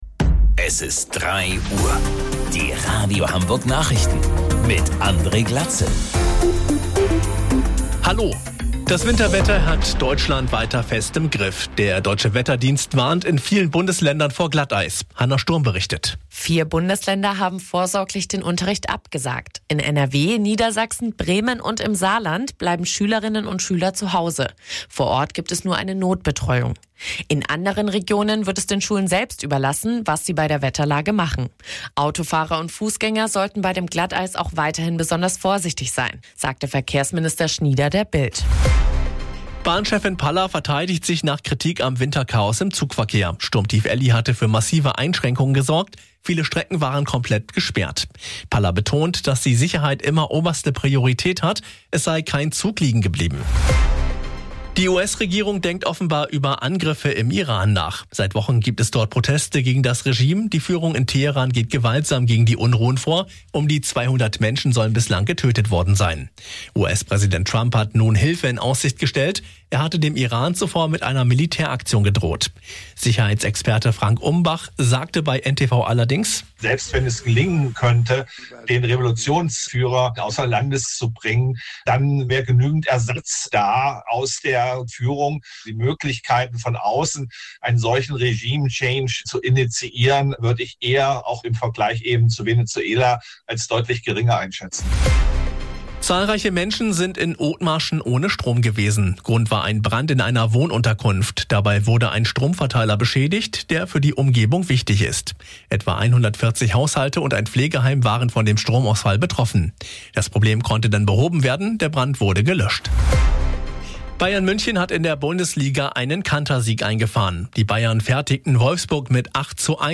Radio Hamburg Nachrichten vom 12.01.2026 um 03 Uhr